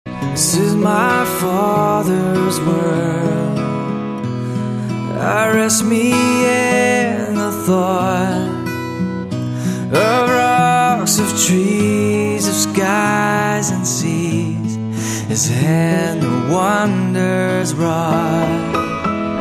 M4R铃声, MP3铃声, 欧美歌曲 83 首发日期：2018-05-14 05:23 星期一